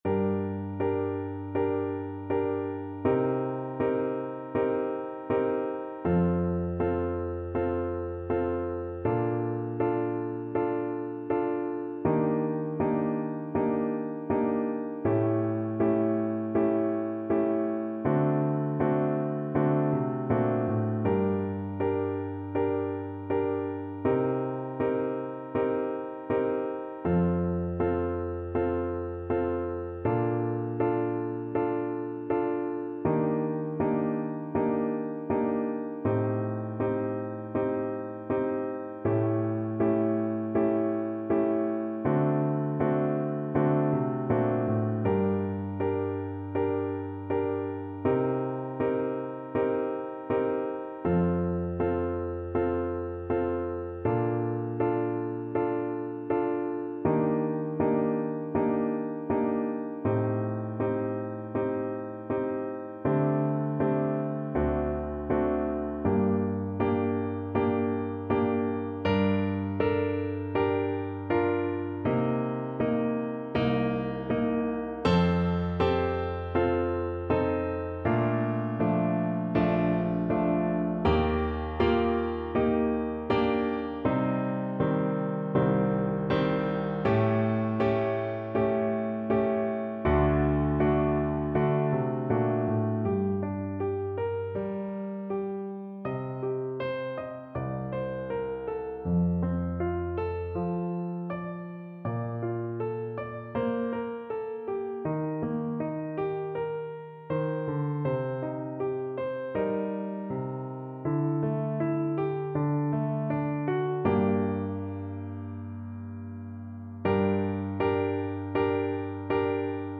4/4 (View more 4/4 Music)
G minor (Sounding Pitch) (View more G minor Music for Tuba )
Andante